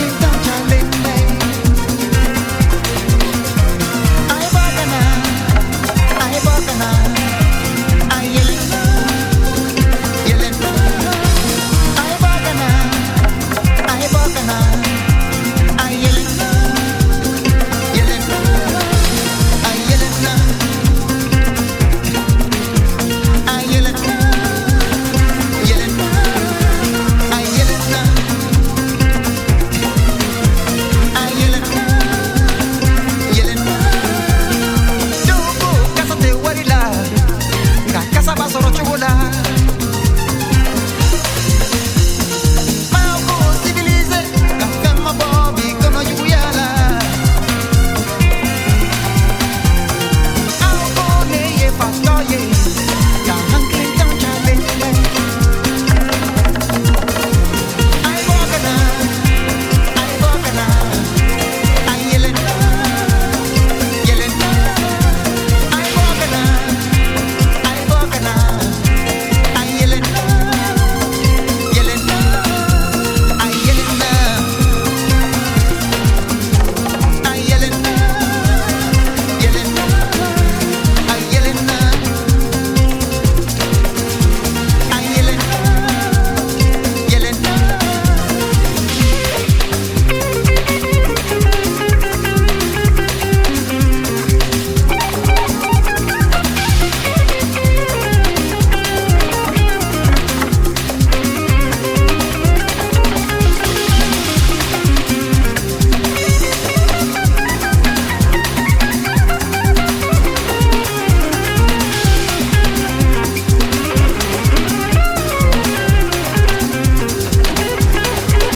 edits and overdubs of African vibrational sounds and rhythms